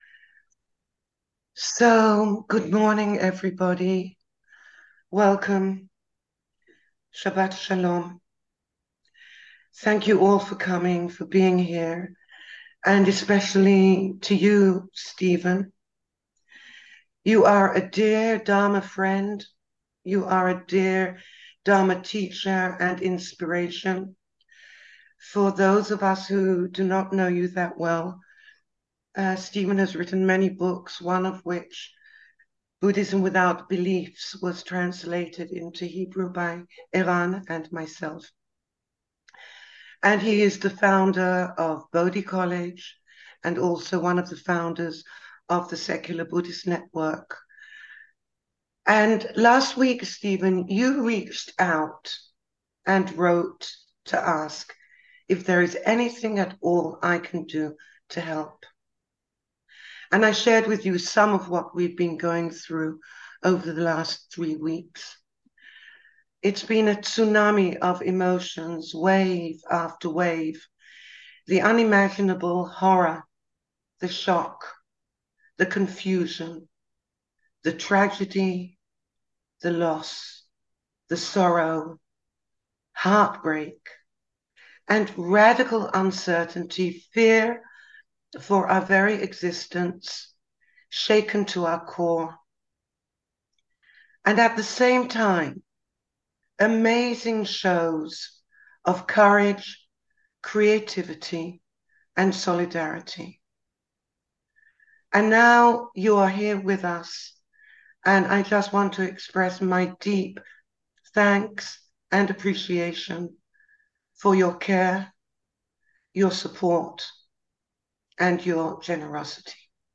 Dharma in Times of Hardship An online talk with Stephen Batchelor